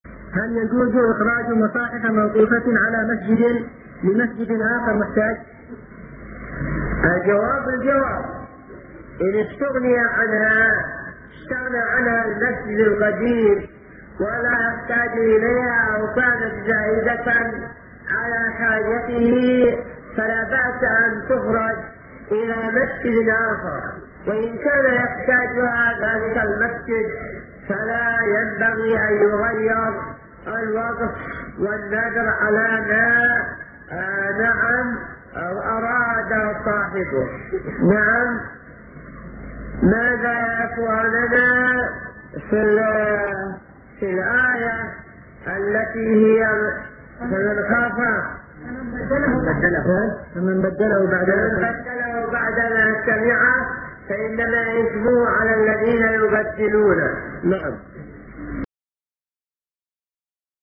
-------------- من شريط : ( الاجوبة المرجانية عن الاسئلة الردفانية ) .